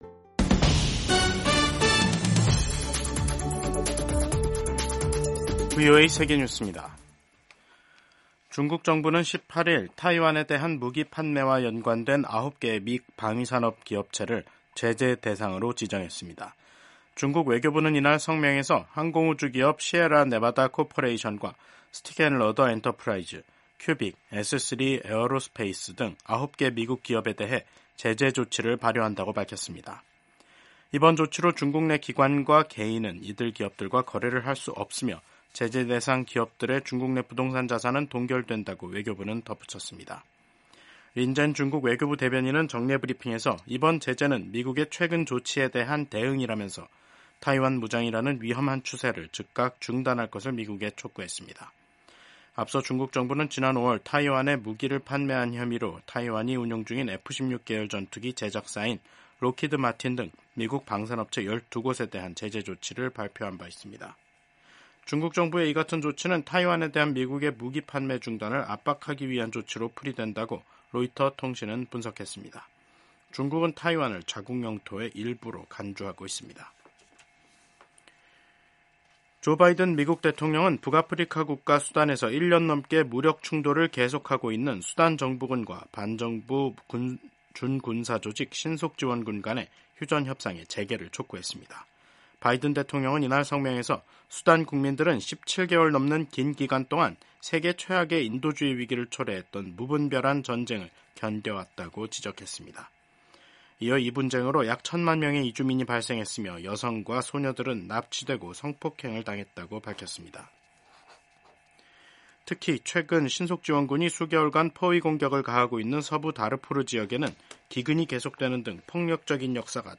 세계 뉴스와 함께 미국의 모든 것을 소개하는 '생방송 여기는 워싱턴입니다', 2024년 9월 18일 저녁 방송입니다. 레바논에서 17일 호출기 수백 대가 폭발해 12명이 숨지고 수천 명이 다쳤습니다.